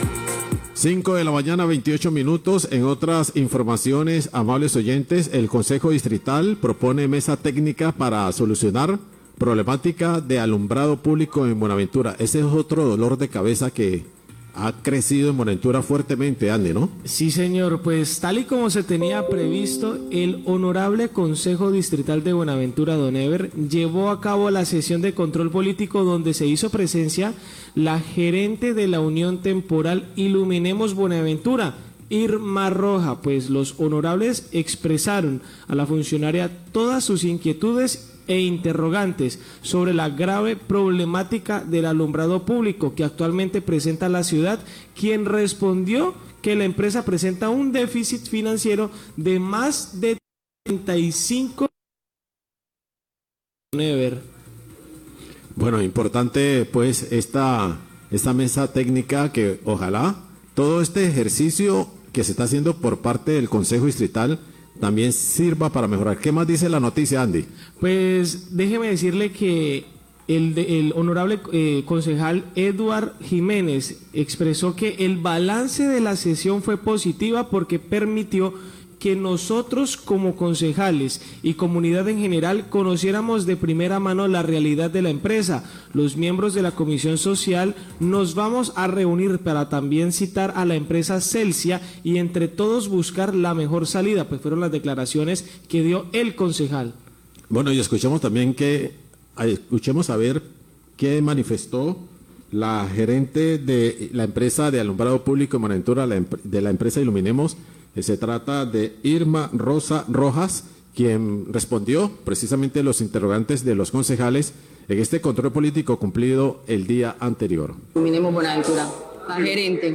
Radio
En el debate de control político realizado por el Concejo Distrital de Buenaventura a la empresa Iluminemos Buenavetura, se determinó el déficit financiero que tiene la empresa por la cual no le permite atender la situación de alumbrado público. Edward Jiménez concejal de Buenaventura, manifestó que se citará a la empresa Celsia a mesas técnicas para entre todos, buscar una solución a la situación.